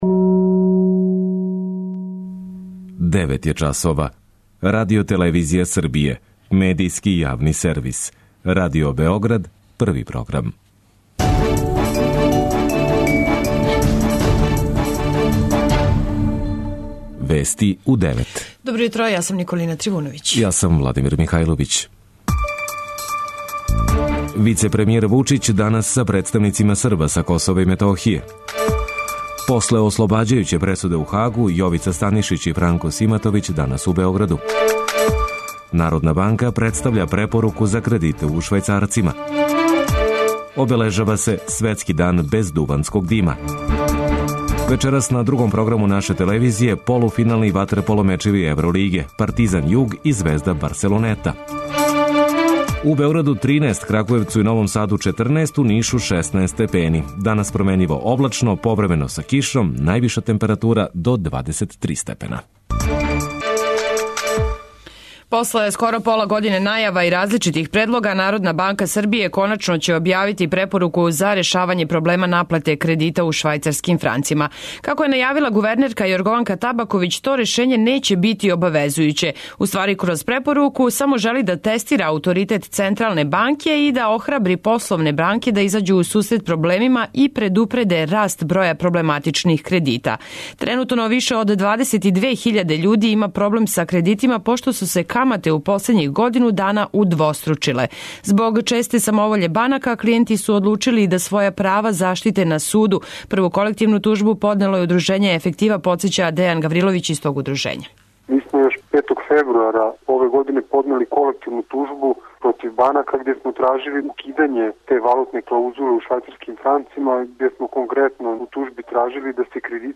преузми : 10.15 MB Вести у 9 Autor: разни аутори Преглед најважнијиx информација из земље из света.